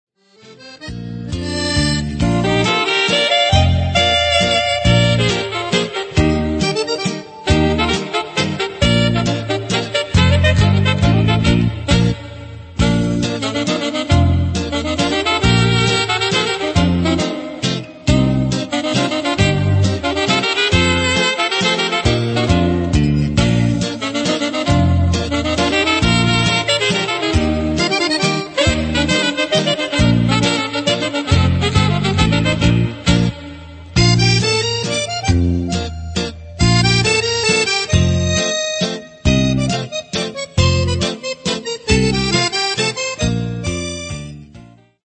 mazurca